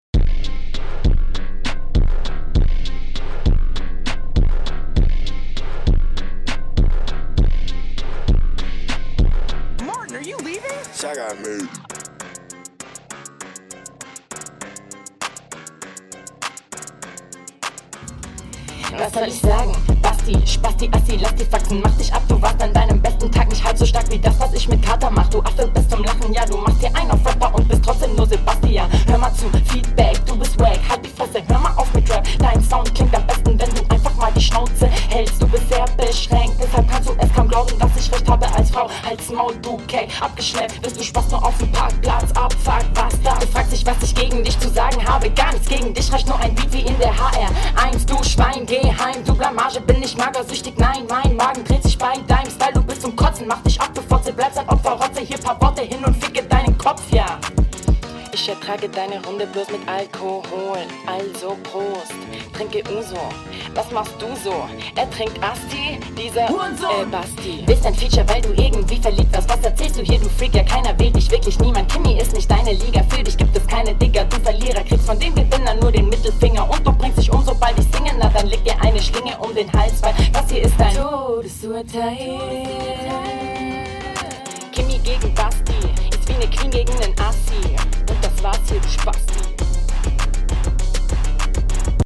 Flow: Flow ist cool. Finde der geht gut nach vorne.
Hmm ja also das mit den Doubles ist erstmal sehr gewöhnungsbedürftig.